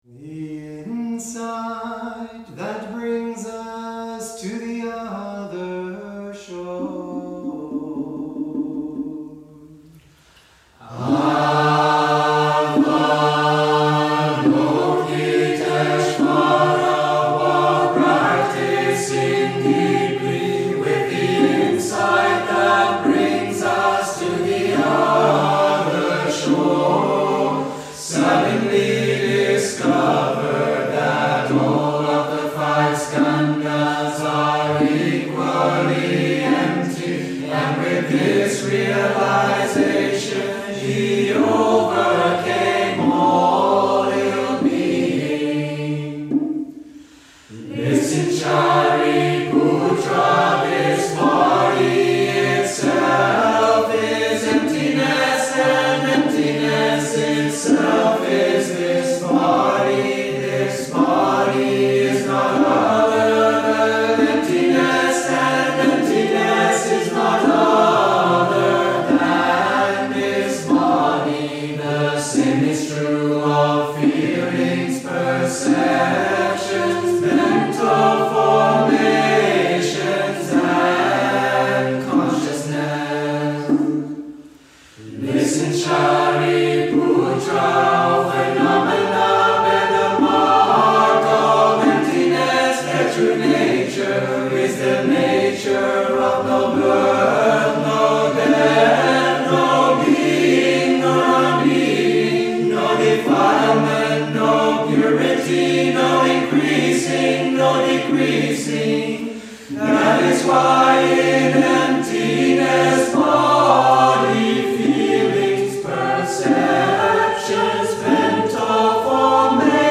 The chant has been set to music. Below you can enjoy a recent recording live in the meditation hall.
Chanted by the brothers and sisters of Plum Village
CAR-The-Insight-That-Brings-Us-to-the-Other-Shore-harmony.mp3